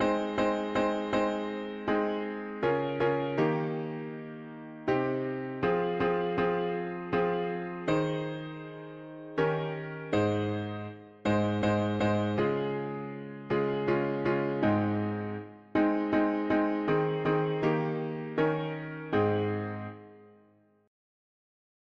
Within our… english theist 4part chords